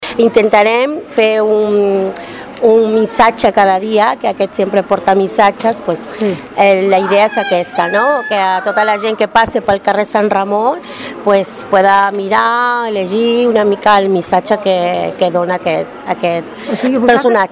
Cada dia, durant la celebració de la festa, s’afegirà un nou missatge per a la població de Tordera que passi per aquell carrer. Ho explica una de les mares.